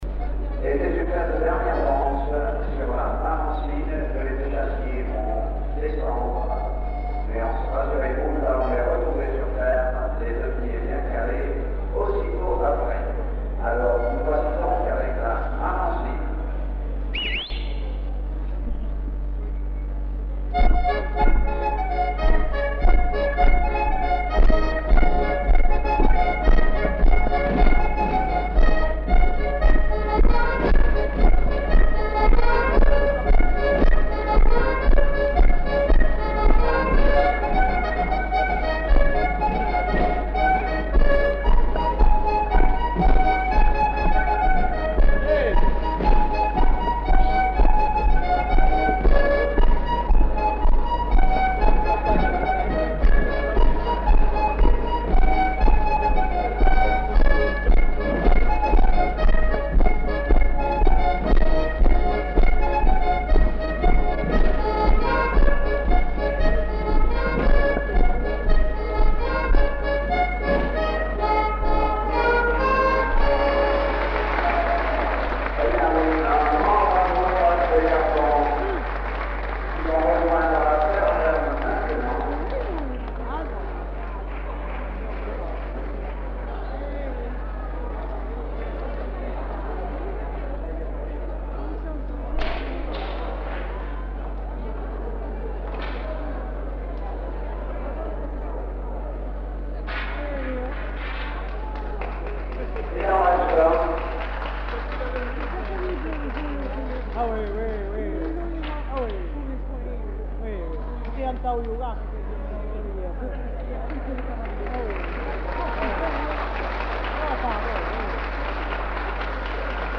(ensemble vocal et instrumental)
Lieu : Samatan
Genre : morceau instrumental
Instrument de musique : accordéon diatonique
Notes consultables : Cet air est aussi connu comme un rondeau des Landes.